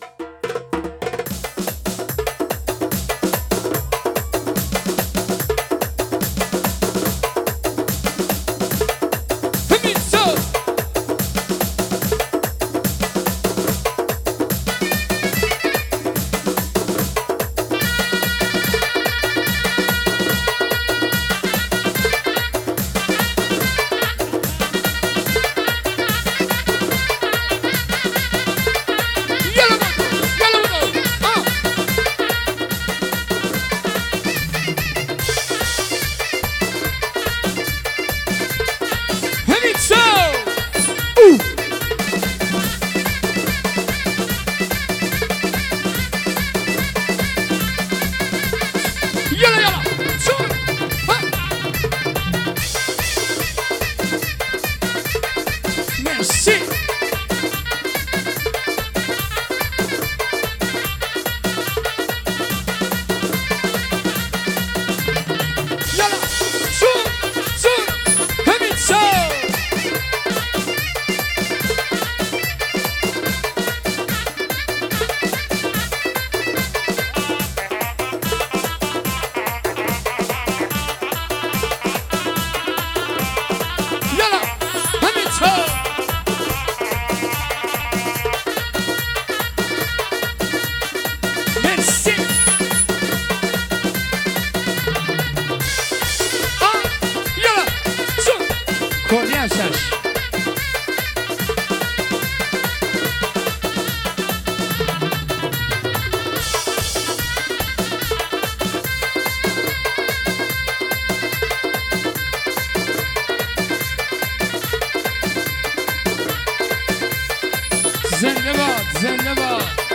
آهنگ بندری ارکستری شاد